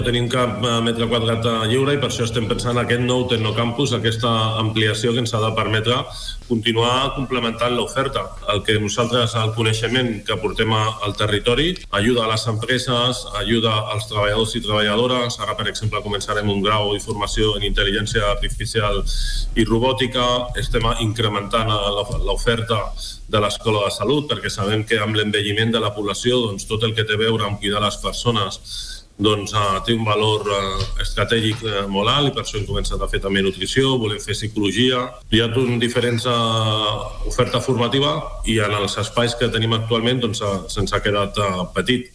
L’alcalde de Mataró, David Bote, ha passat per l’Entrevista del Dia de RCT per parlar del nou projecte d’ampliació del TecnoCampus.